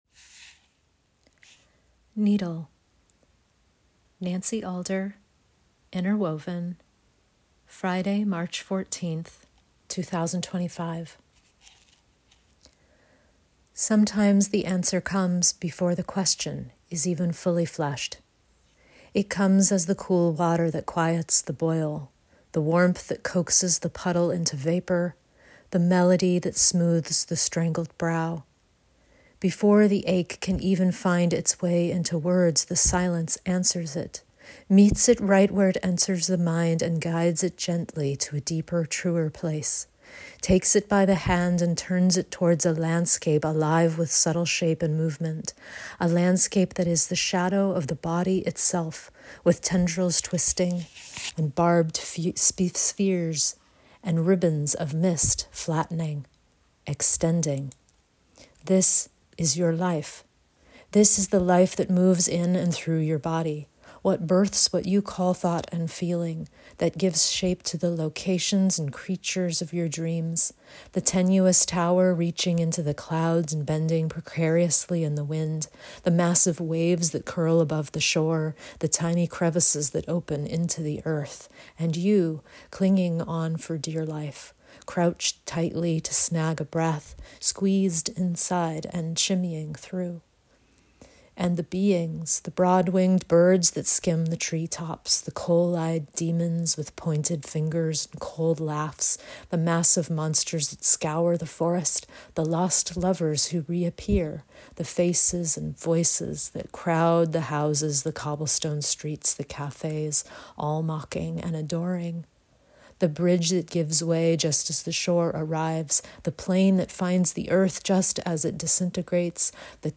Enjoy this 10-minute read, or listen to my voice reading it via the link at the top of my webpage!